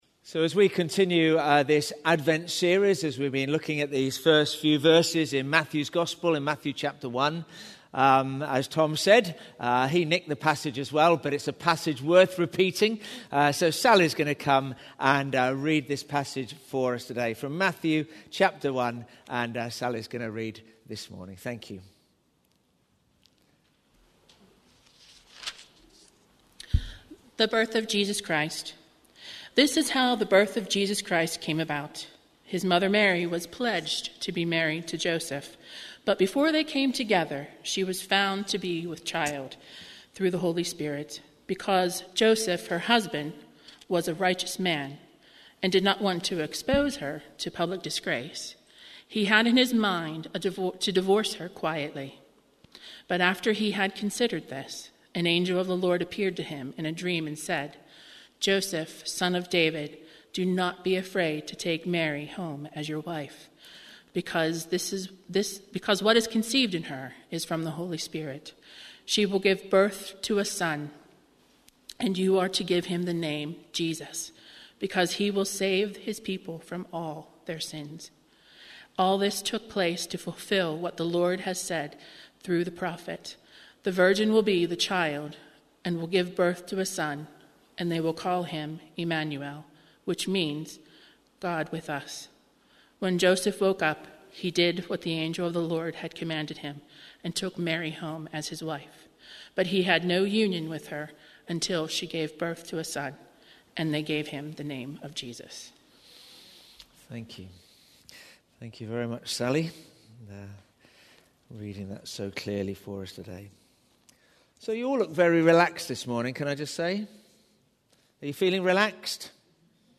Today's sermon is based on Matthew 1:22-24 looking at what's in the purpose for which Jesus came.